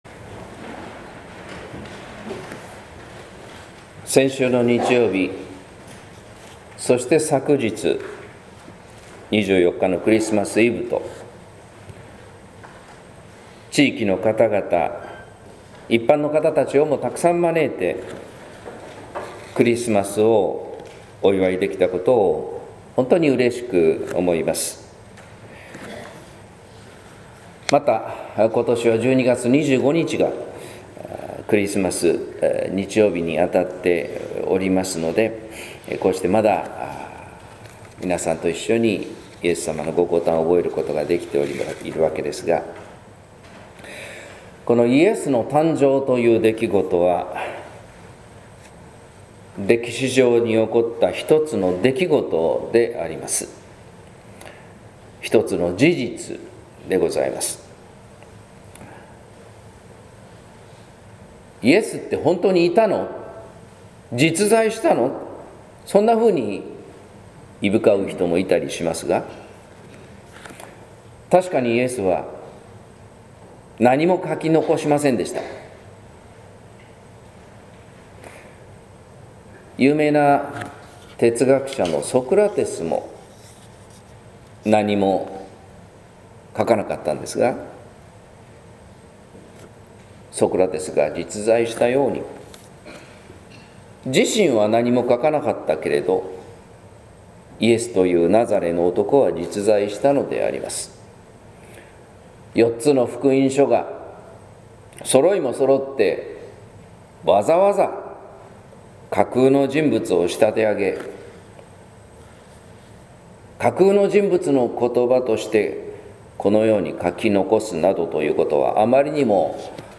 説教「ことばと光に宿るいのち」（音声版） | 日本福音ルーテル市ヶ谷教会